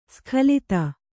♪ skhalita